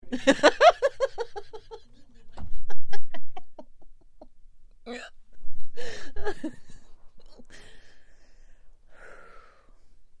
女声笑声音效_人物音效音效配乐_免费素材下载_提案神器
女声笑声音效免费音频素材下载